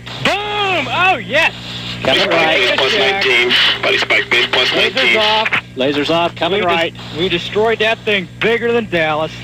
AGKillBOOM1.ogg